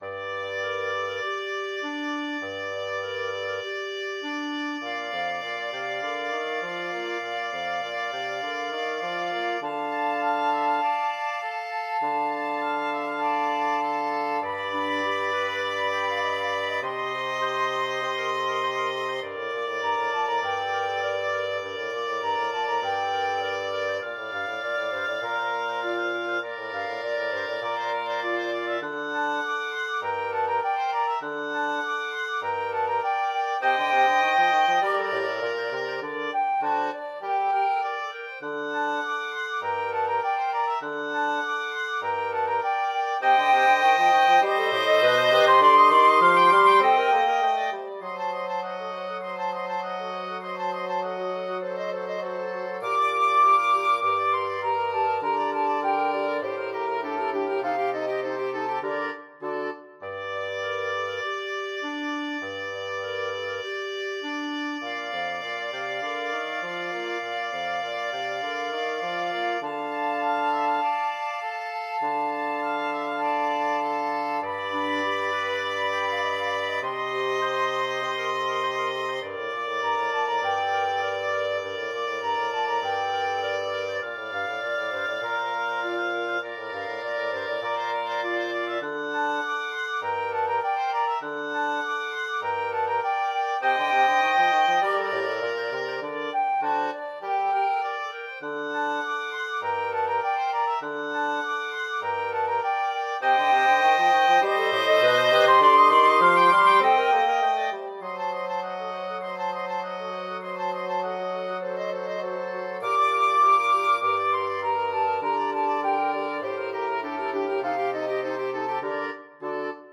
Free Sheet music for Flexible Mixed Ensemble - 4 Players
FluteViolin
OboeViolaFlute
Clarinet in BbFlute
BassoonBass Clarinet in Bb
G minor (Sounding Pitch) (View more G minor Music for Flexible Mixed Ensemble - 4 Players )
Allegro Moderato (View more music marked Allegro)
4/4 (View more 4/4 Music)
Classical (View more Classical Flexible Mixed Ensemble - 4 Players Music)